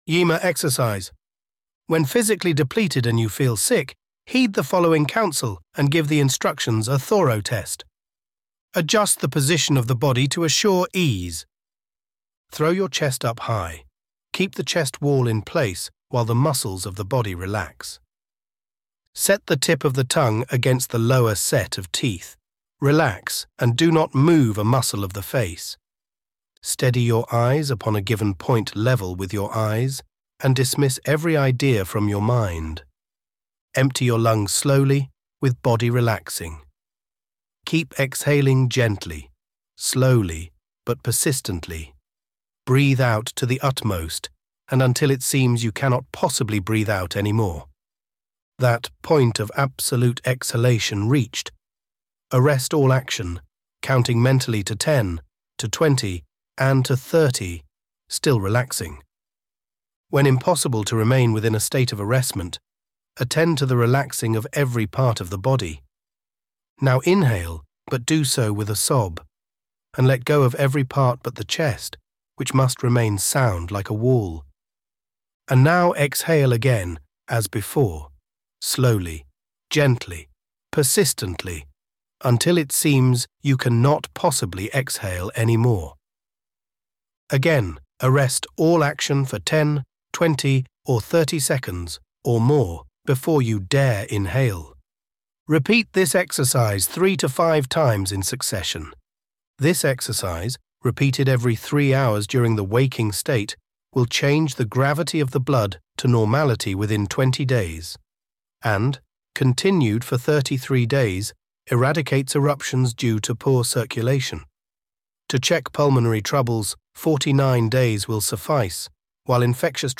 (spoken by Elevenlabs George)